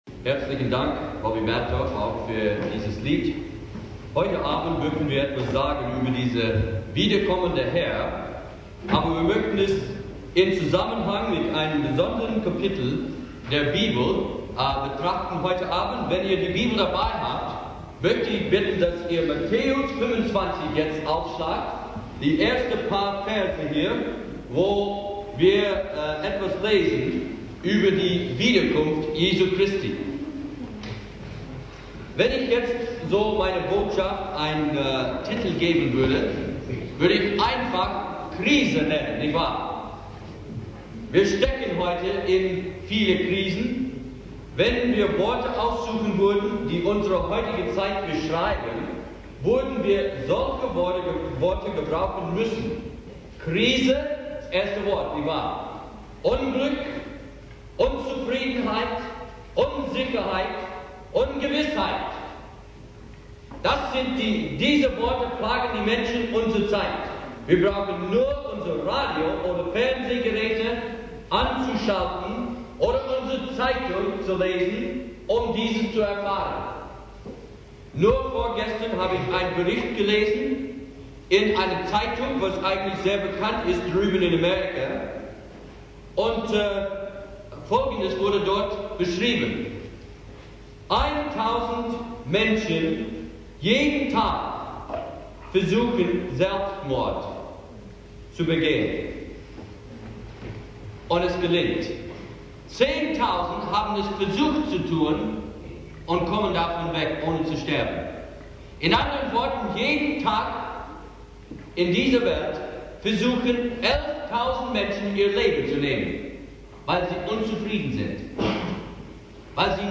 Predigt
2. Abend der Jugendevangelisation